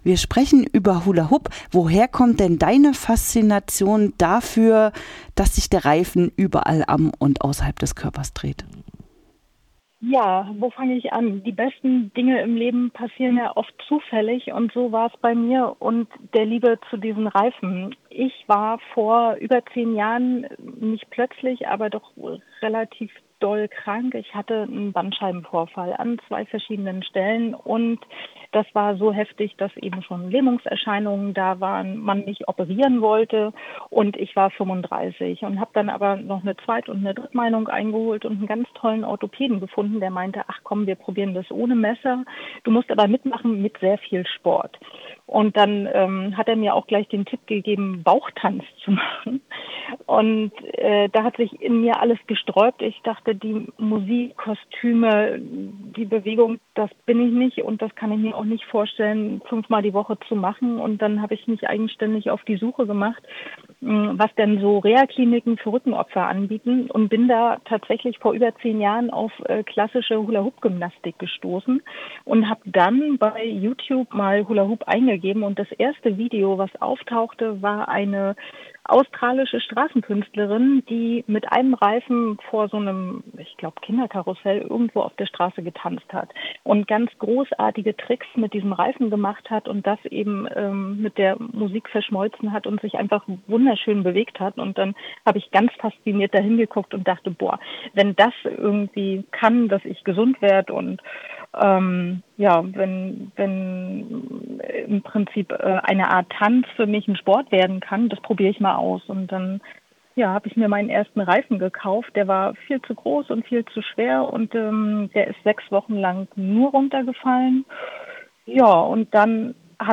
Hula Hoop - Die Liebe zu den Reifen | Interview